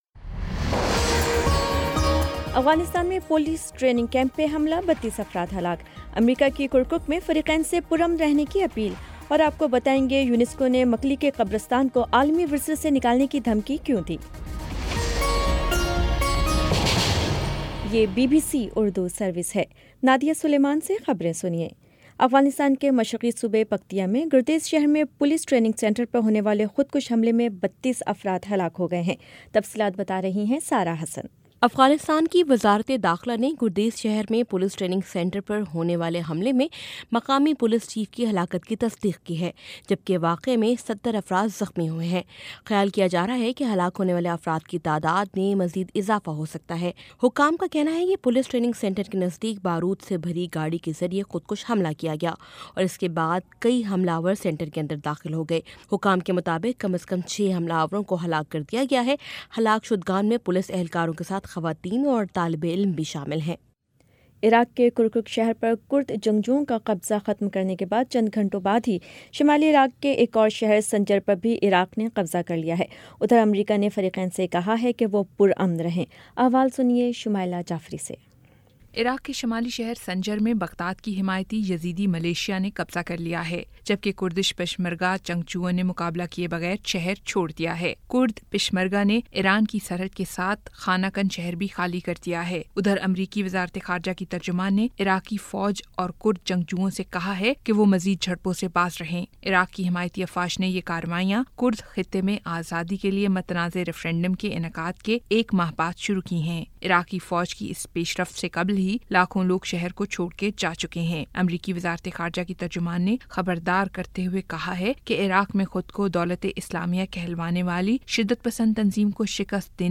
اکتوبر 17 : شام چھ بجے کا نیوز بُلیٹن